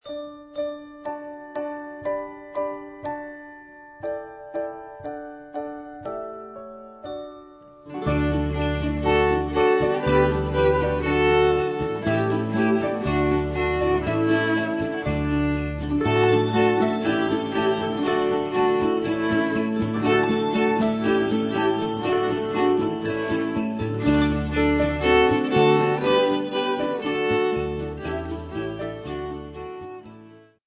32 Weihnachtslieder aus verschiedenen Ländern für 1-2 Violen
Besetzung: 1-2 Violen mit CD